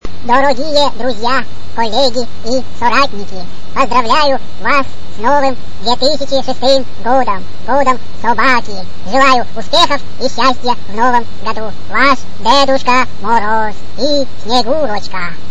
Звуковое Поздравление Всем с Новым Годом!